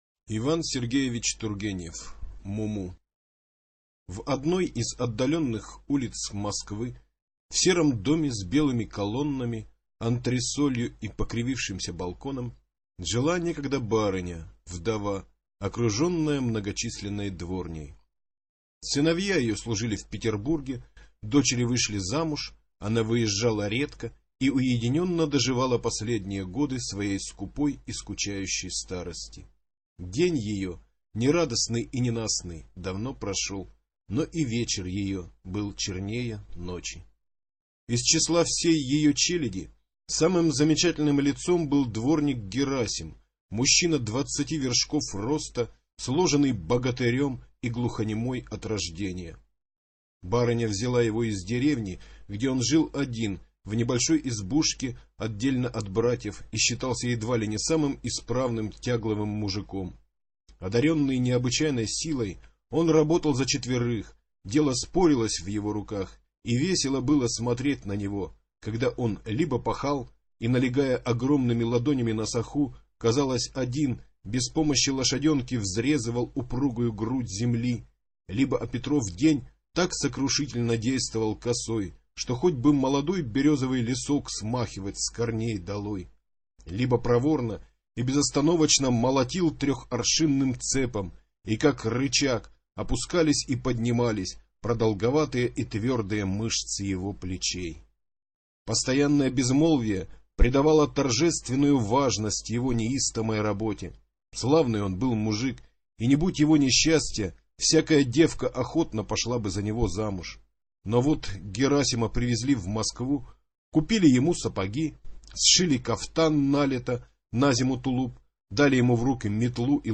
Муму - Тургенев - слушать рассказ онлайн